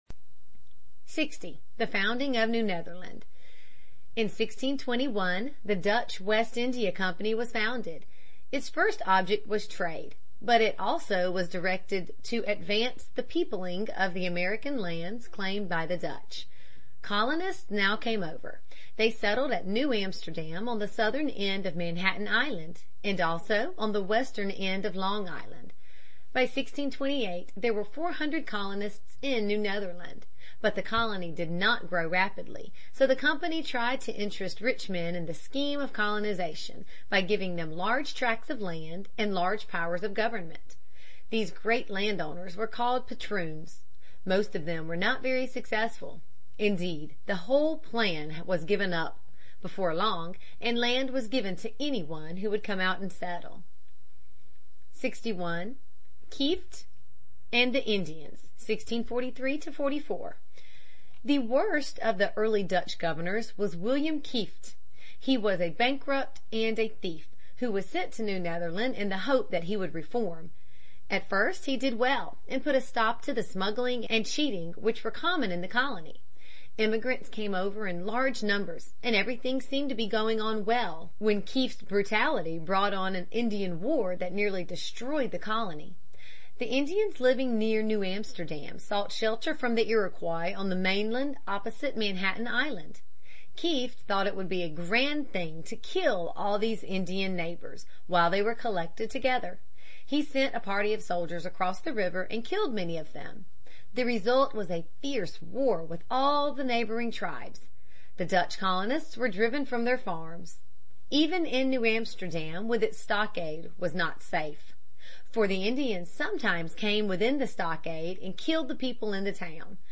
在线英语听力室美国学生历史 第20期:新荷兰和新瑞典(2)的听力文件下载,这套书是一本很好的英语读本，采用双语形式，配合英文朗读，对提升英语水平一定更有帮助。